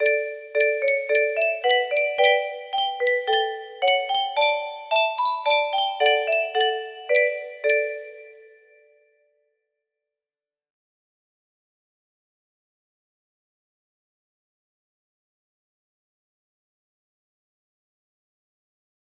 music boxes